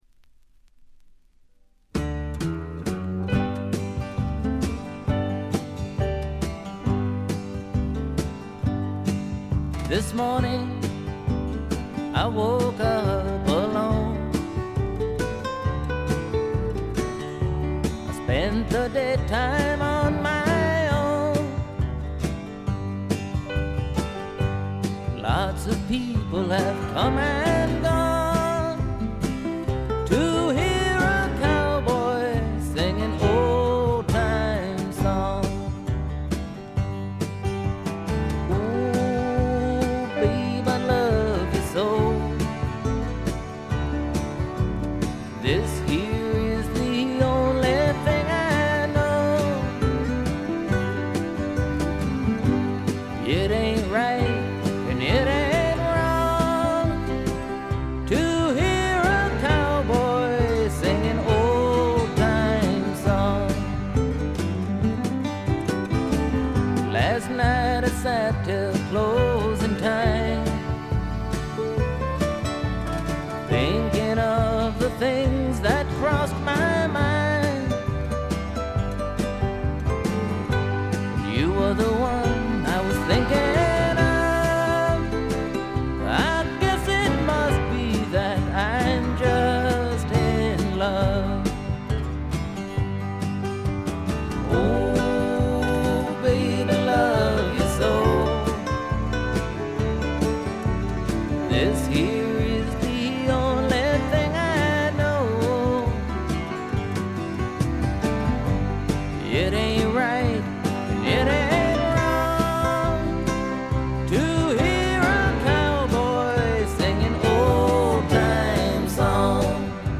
これ以外はほとんどノイズ感無し。
試聴曲は現品からの取り込み音源です。